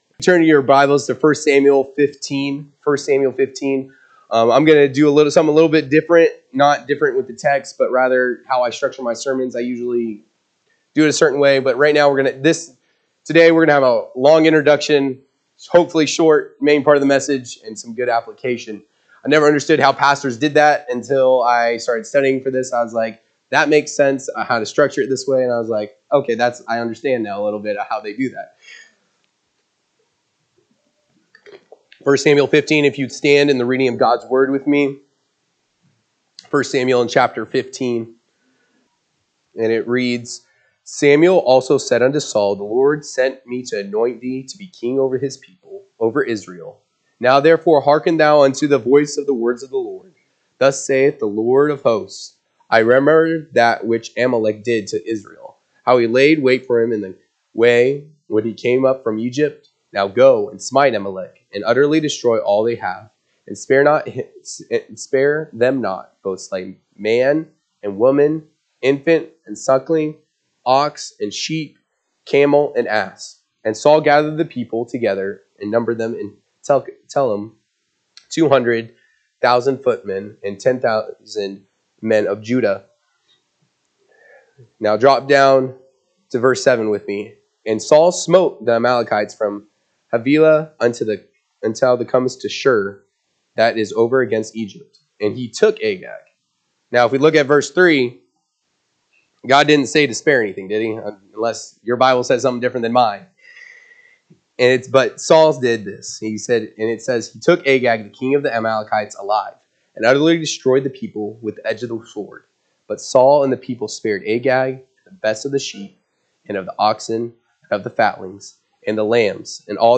April 27, 2025 am Service 1 Samuel 15:1-4, 7-22 (KJB) 15 Samuel also said unto Saul, The Lord sent me to anoint thee to be king over his people, over Israel: now therefore hearken th…